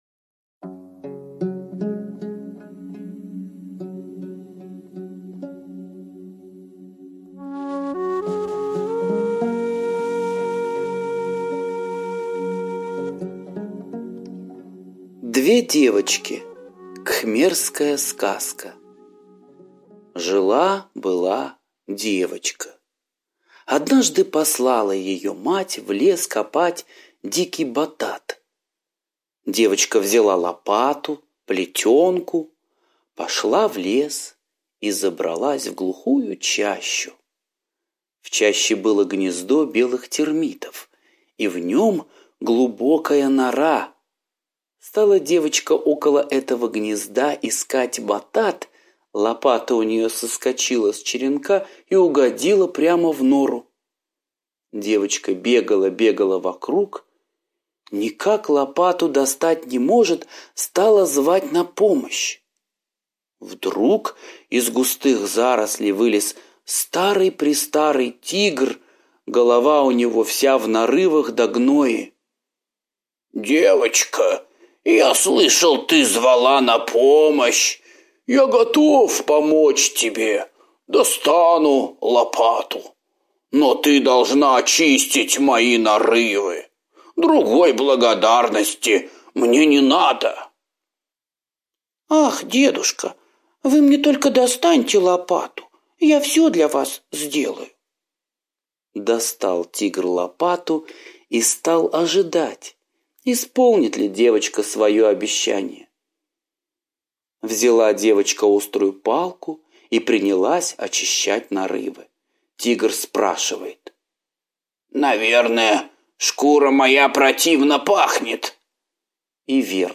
Две девочки - восточная аудиосказка - слушать онлайн